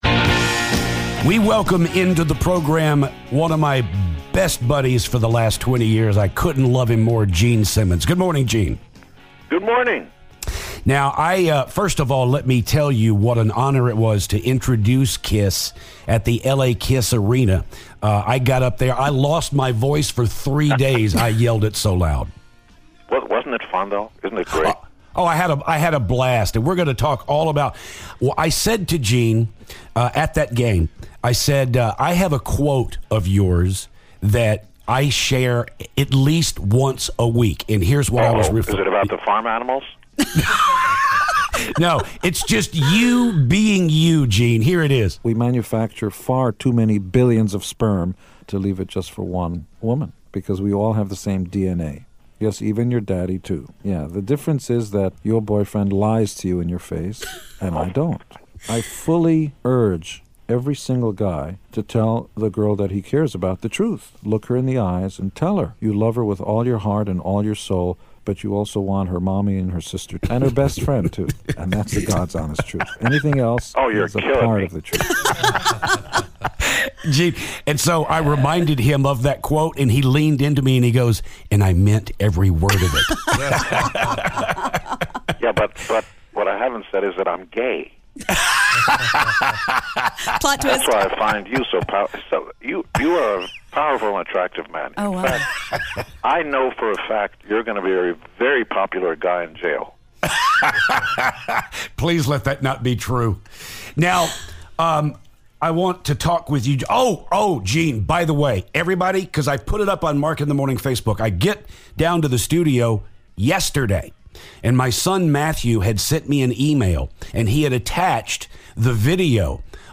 Gene Simmons calls the show